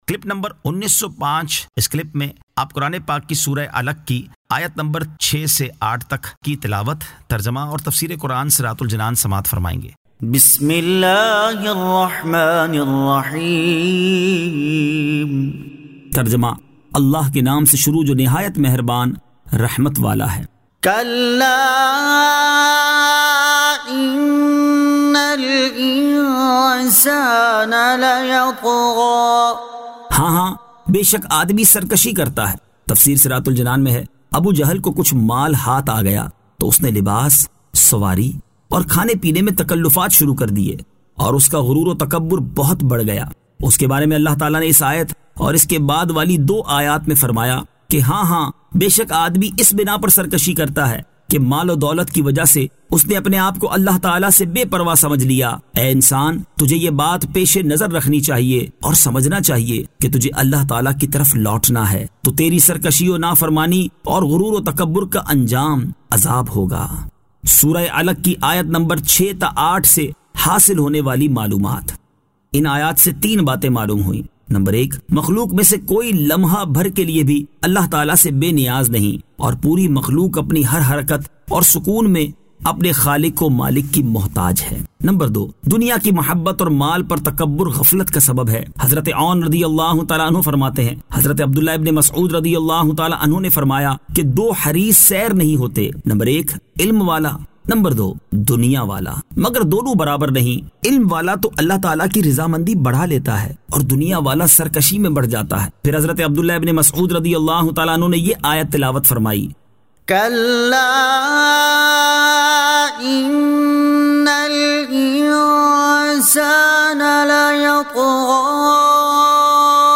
Surah Al-Alaq 06 To 08 Tilawat , Tarjama , Tafseer